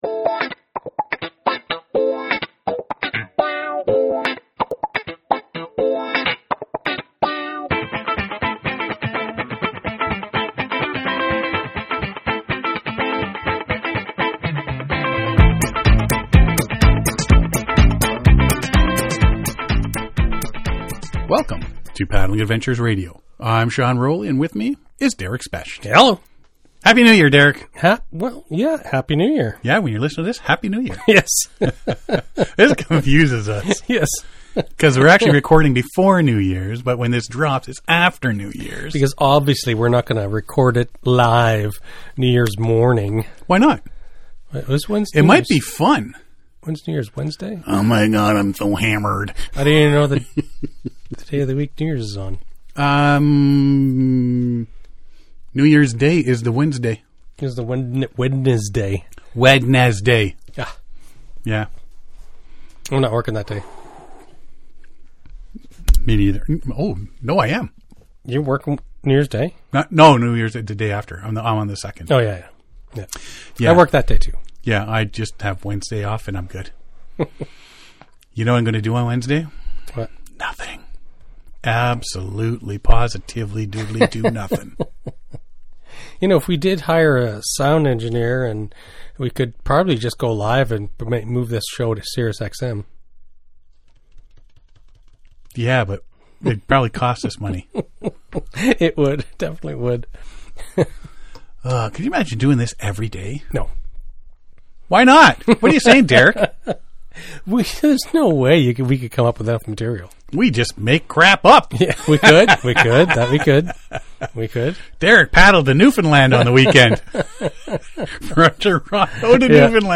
We discuss all paddlesports. News, interviews, and information from, about, and for the paddling community.